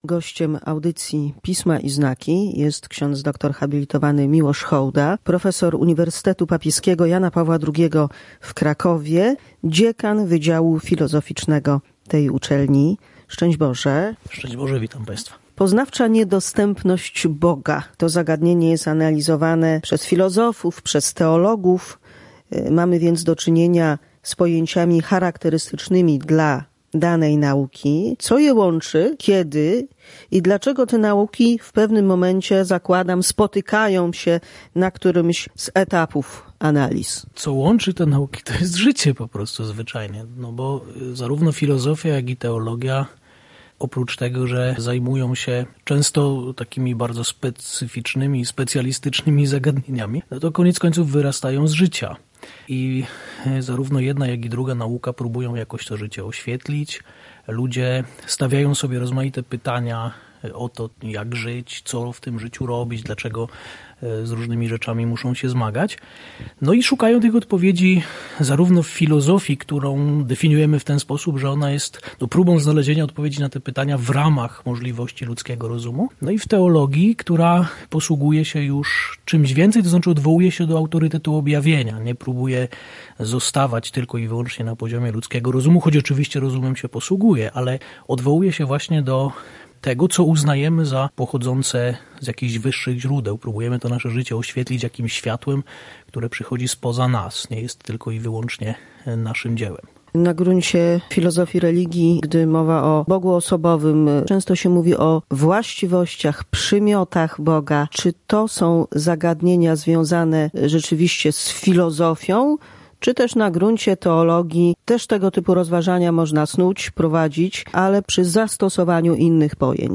W tym wydaniu audycji Pisma i znaki zastanawiamy się nad poznawczą niedostępnością Boga, przybliżamy genezę pojęcia absconditeizm. Co sprawia, że od wieków człowiek pyta o obecność absolutu w świecie, dziejach, przyrodzie, życiu własnym i innych? To tylko niektóre z zagadnień rozwijanych podczas rozmowy.